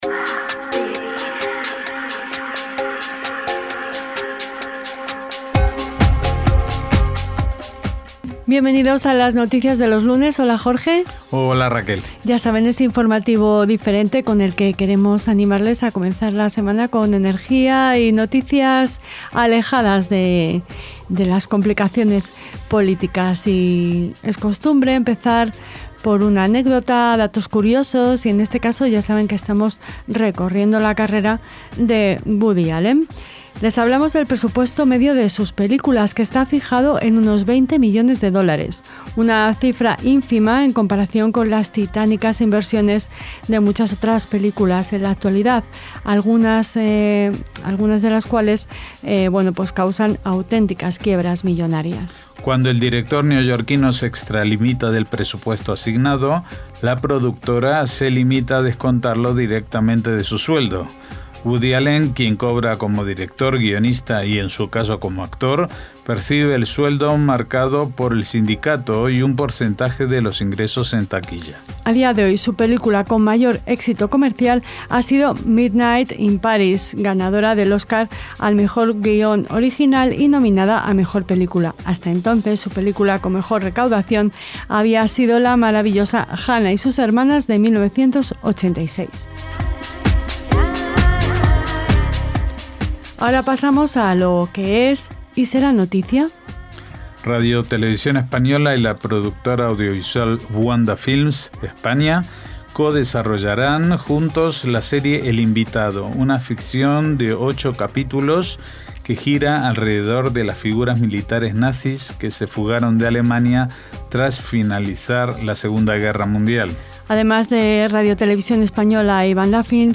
La música de Lus de Sefarad nos acompaña en un paseo por el archivo histórico de Radio Sefarad, justo después de que les hayamos presentado La frase de hoy, una de esas imprescindibles: ¿Si yo no hago por mí, quién hará por mí?